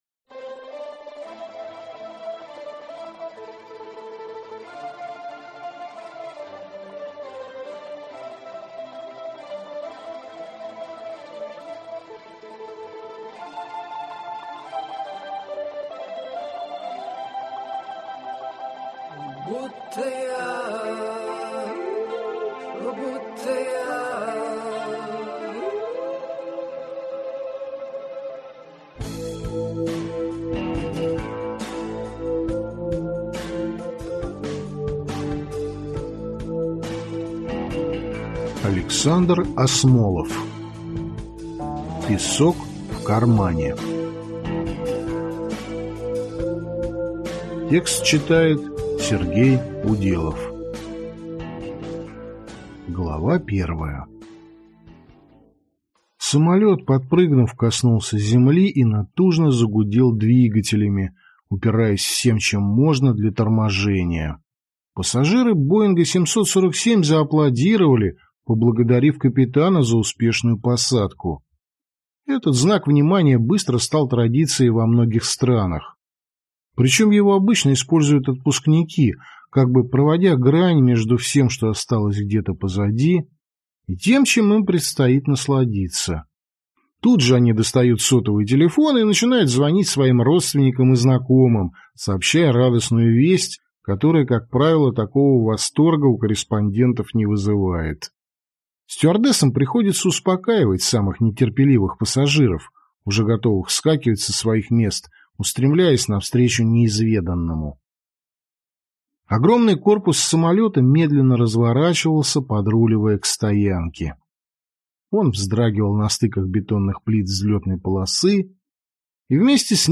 Аудиокнига Песок в кармане | Библиотека аудиокниг